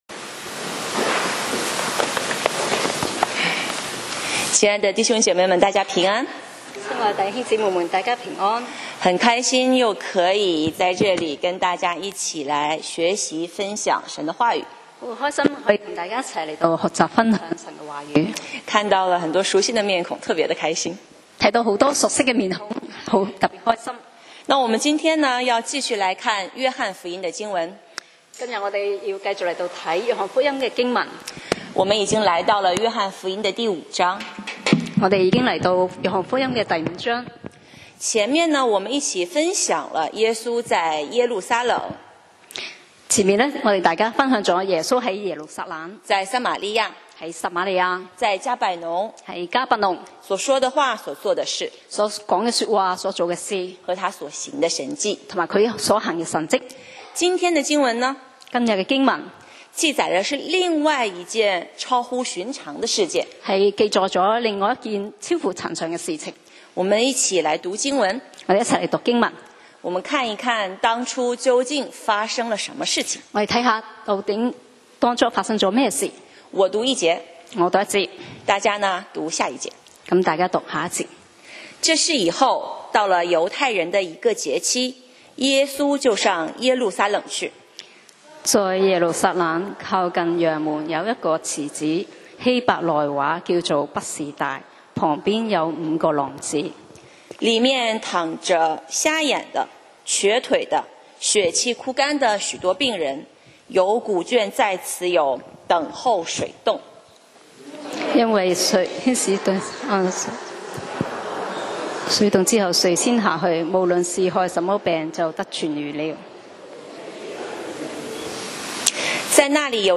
講道 Sermon 題目 Topic：癱着？起來？！